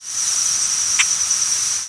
Boat-tailed Grackle diurnal flight calls
"Khek" call with insect noise.